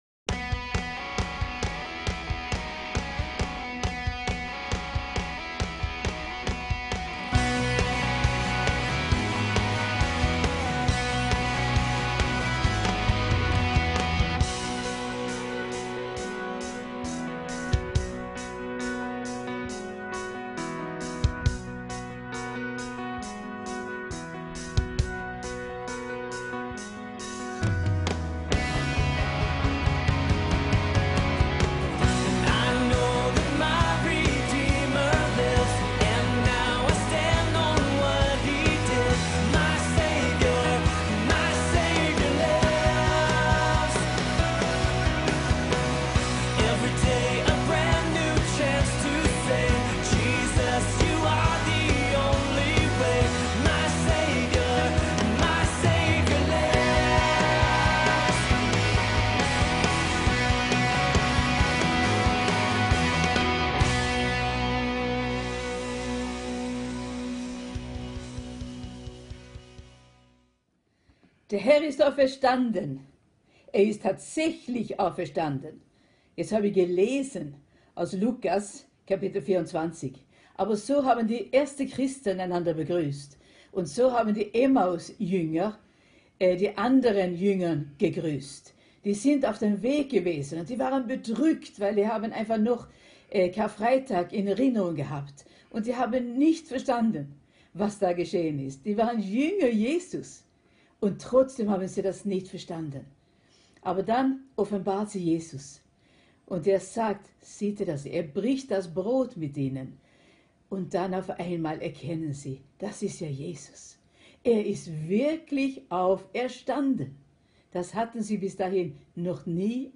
OSTERGOTTESDIENST 2020